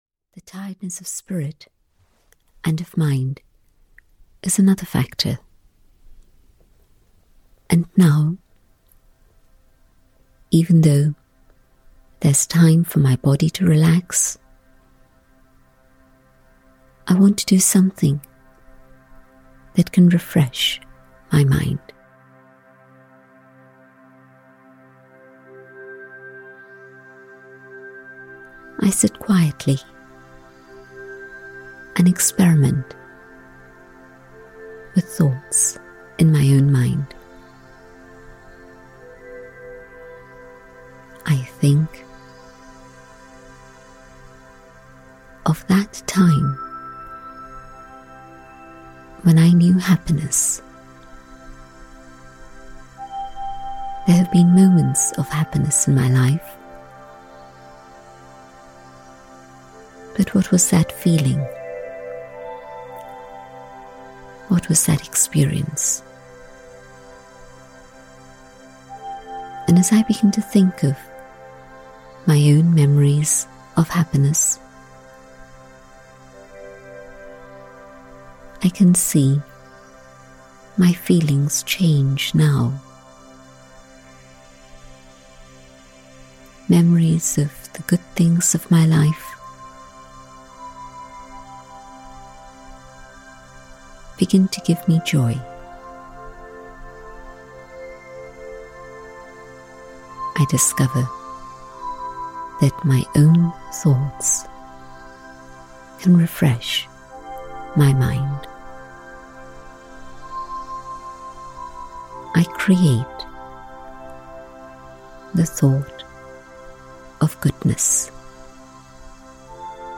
Audio knihaInner Light (EN)
Ukázka z knihy